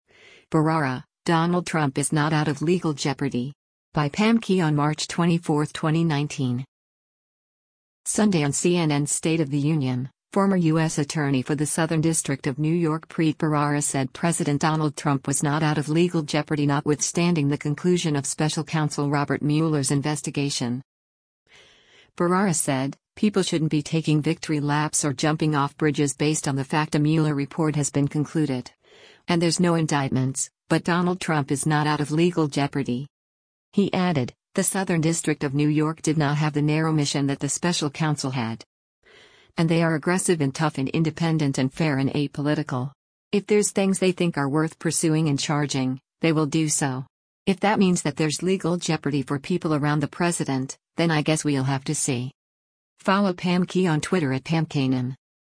Sunday on CNN’s “State of the Union,” former U.S. Attorney for the Southern District of New York Preet Bharara said President Donald Trump was “not out of legal jeopardy” notwithstanding the conclusion of special counsel Robert Mueller’s investigation.